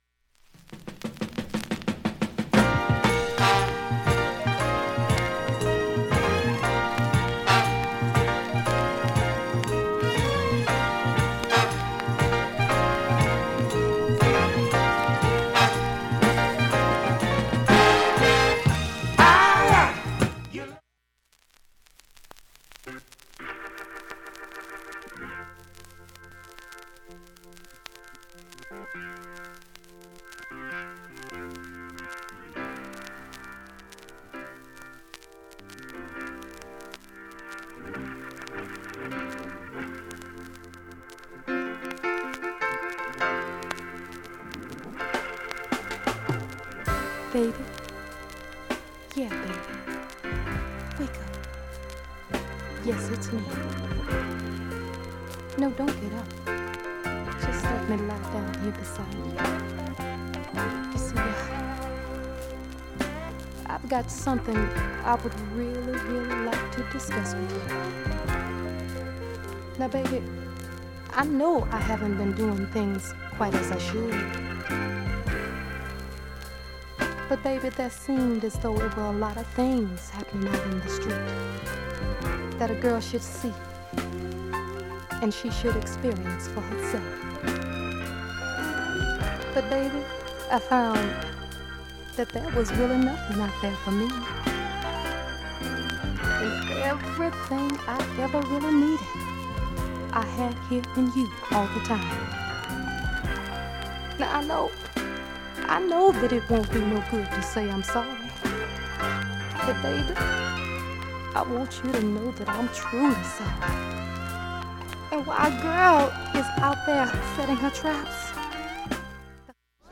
大半の曲はチリも無く、
音質良好全曲試聴済み。
７回までのかすかなプツが２箇所
５回までのかすかなプツが８箇所